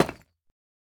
Minecraft Version Minecraft Version latest Latest Release | Latest Snapshot latest / assets / minecraft / sounds / block / deepslate_bricks / place4.ogg Compare With Compare With Latest Release | Latest Snapshot